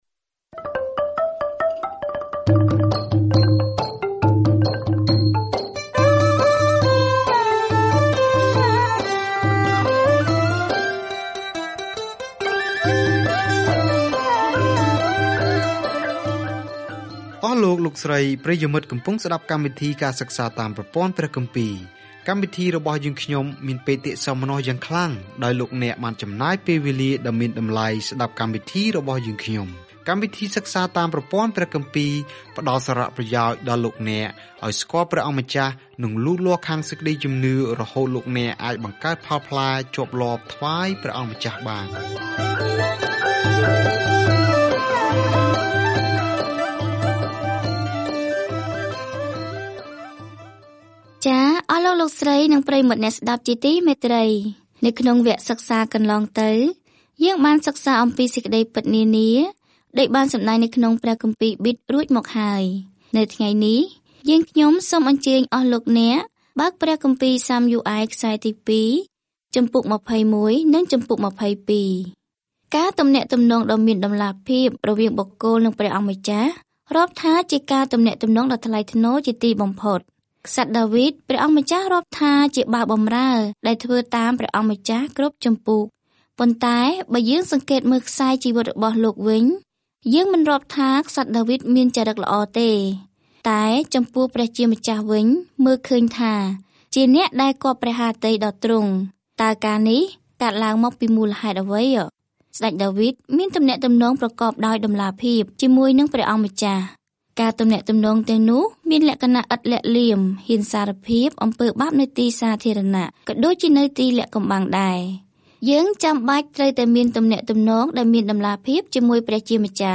រឿងនៃទំនាក់ទំនងរបស់អ៊ីស្រាអែលជាមួយព្រះបន្តជាមួយនឹងការណែនាំរបស់ព្យាការីក្នុងបញ្ជីនៃរបៀបដែលព្រះភ្ជាប់ជាមួយនឹងរាស្ដ្ររបស់ទ្រង់។ ការធ្វើដំណើរប្រចាំថ្ងៃតាមរយៈ 2 សាំយូអែល នៅពេលអ្នកស្តាប់ការសិក្សាជាសំឡេង ហើយអានខគម្ពីរដែលជ្រើសរើសចេញពីព្រះបន្ទូលរបស់ព្រះ។